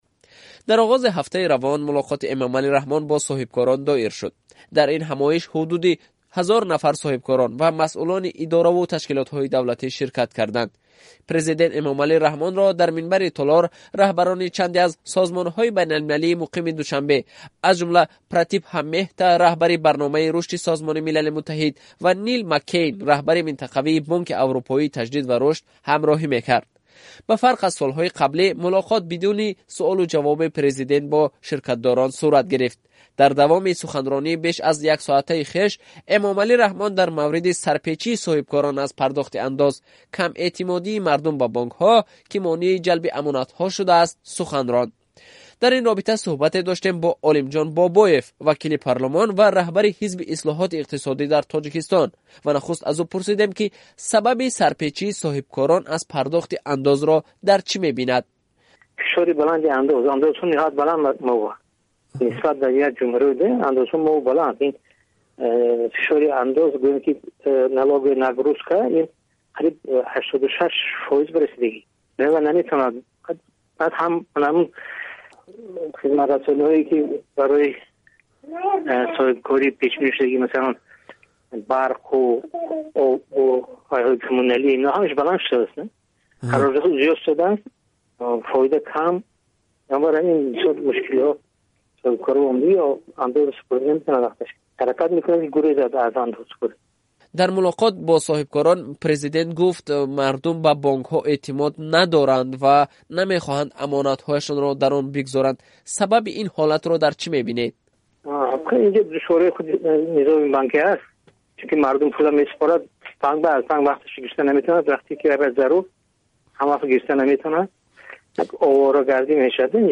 Гуфтугӯ бо Олимҷон Бобоев